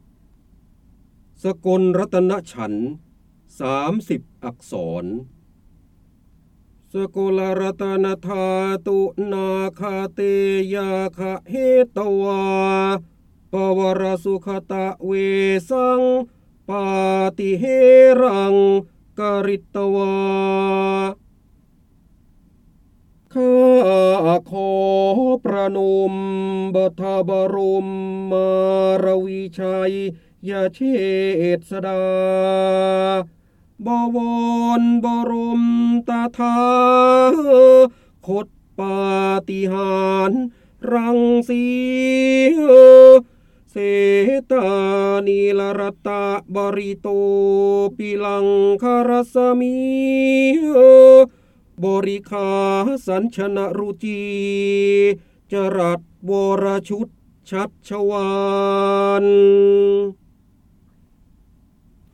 เสียงบรรยายจากหนังสือ จินดามณี (พระโหราธิบดี) สกลรัตนฉันท ๓o อักษร
คำสำคัญ : จินดามณี, พระเจ้าบรมโกศ, ร้อยกรอง, การอ่านออกเสียง, ร้อยแก้ว, พระโหราธิบดี